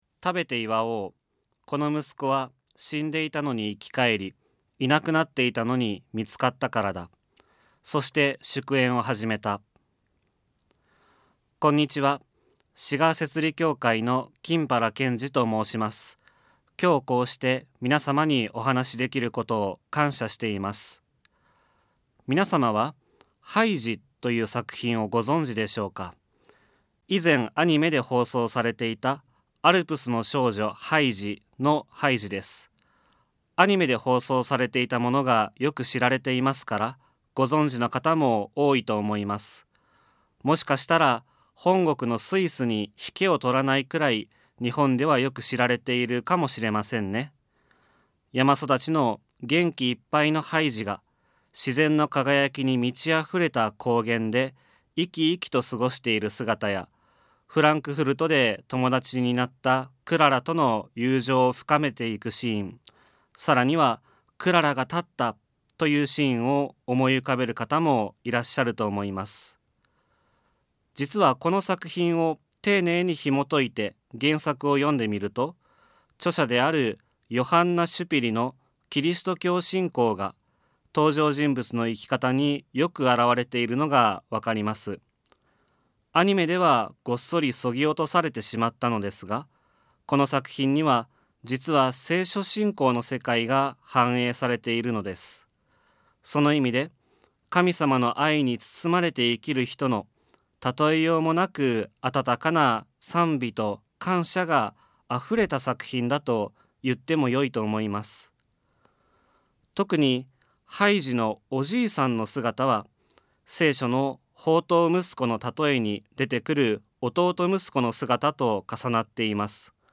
」 聖書はルカによる福音書より ラジオ番組「キリストへの時間」